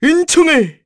Lucias-vox-Happy4_kr.wav